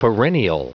Prononciation du mot perennial en anglais (fichier audio)
Prononciation du mot : perennial